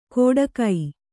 ♪ kōḍa kai